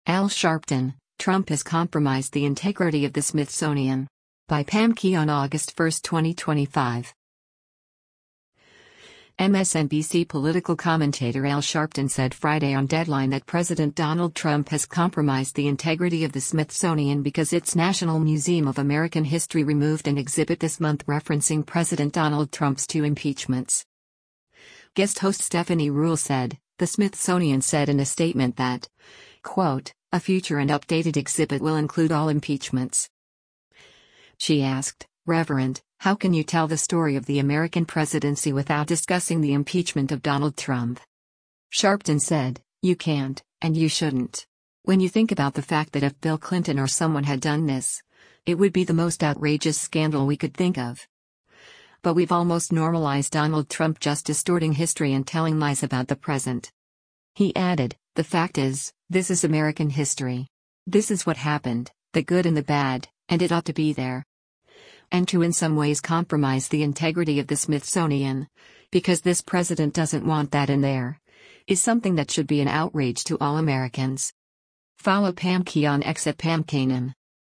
MSNBC political commentator Al Sharpton said Friday on “Deadline” that President Donald Trump has compromised the “integrity of the Smithsonian” because it’s National Museum of American History removed an exhibit this month referencing President Donald Trump’s two impeachments.
Guest host Stephanie Ruhle said, “The Smithsonian said in a statement that, quote, ‘a future and updated exhibit will include all impeachments.'”